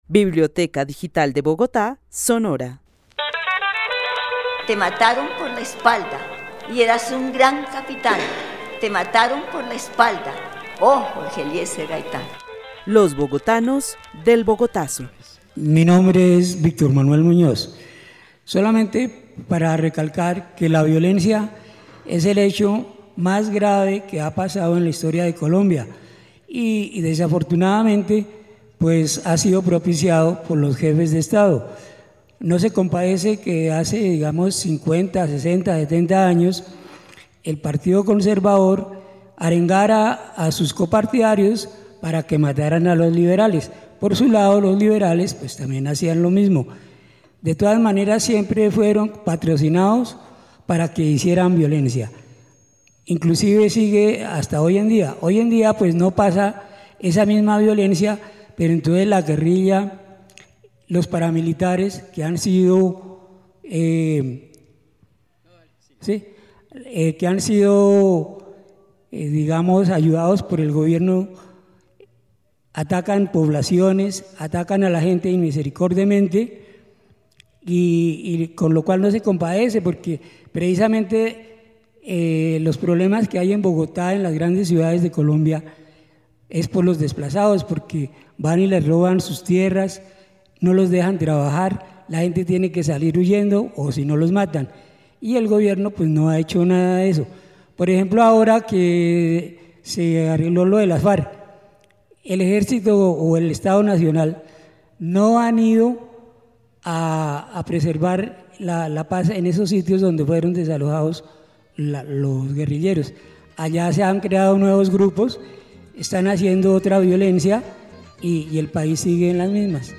Narración oral sobre la naturaleza de la violencia en Colombia, luego de los hechos sucedidos en Bogotá el 9 de abril de 1948. Refiere la acción de los partidos políticos en la labor de incentivar la violencia entre los colombianos.
El testimonio fue grabado en el marco de la actividad "Los bogotanos del Bogotazo" con el club de adultos mayores de la Biblioteca Carlos E. Restrepo.